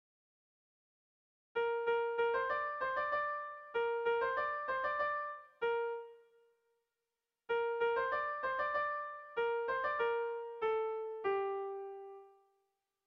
Sehaskakoa
Lauko txikia (hg) / Bi puntuko txikia (ip)
A1A2